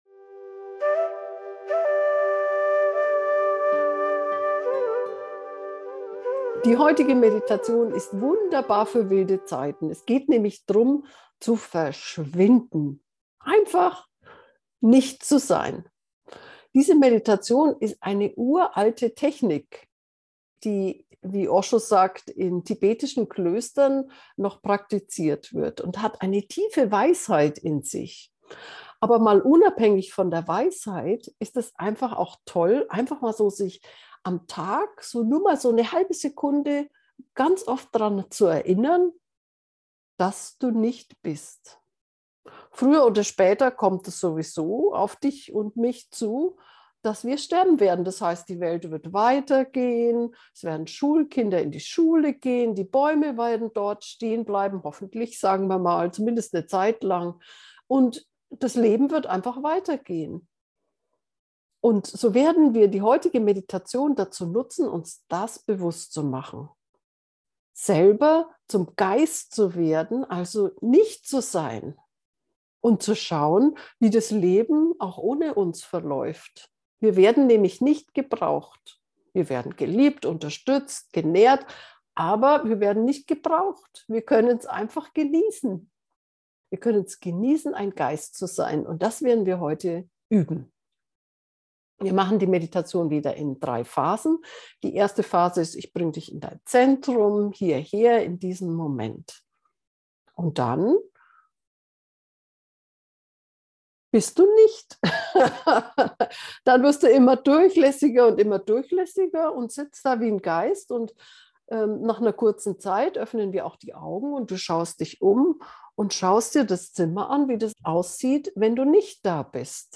verschwinde-nichtsein-gefuehrte-meditation.mp3